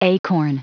Prononciation du mot acorn en anglais (fichier audio)
Prononciation du mot : acorn